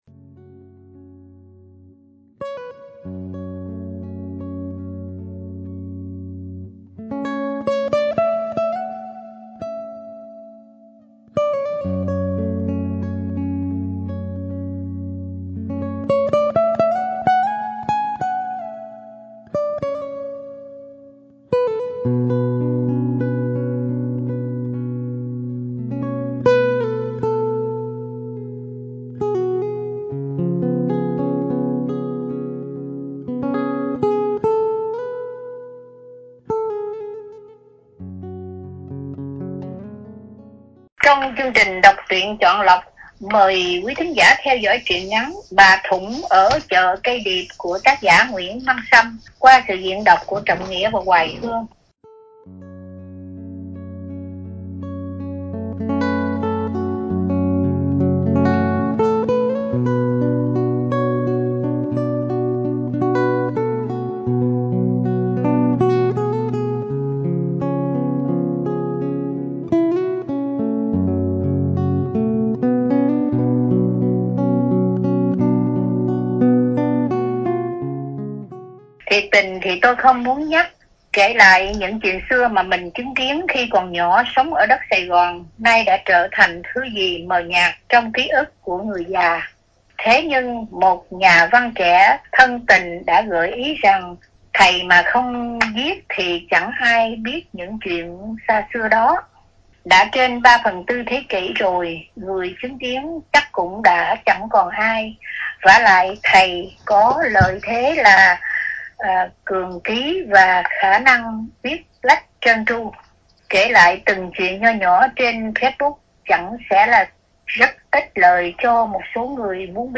Đọc Truyện Chọn Lọc – Bà Thũng Ở Chợ Cây Điệp- Tác Giả Nguyễn Văn Sâm – Radio Tiếng Nước Tôi San Diego